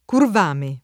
[ kurv # me ]